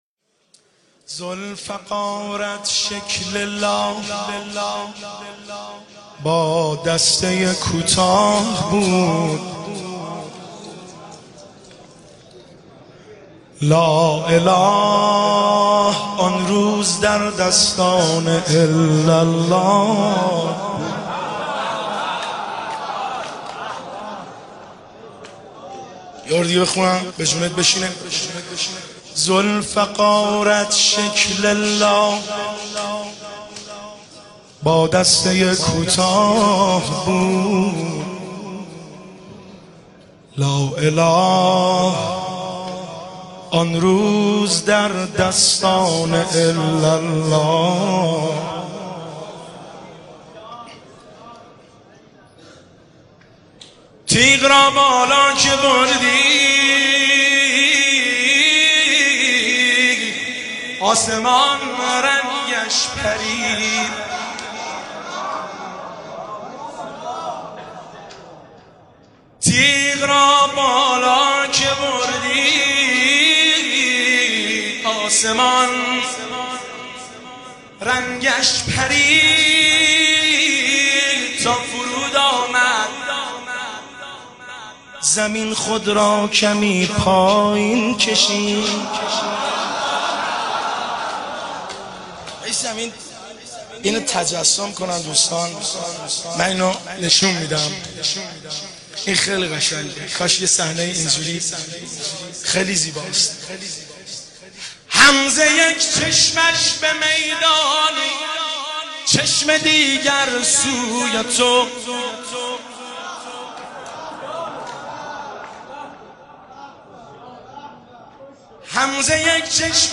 شعرخوانی شب 19 رمضان المبارک 1394
هیئت بین الحرمین طهران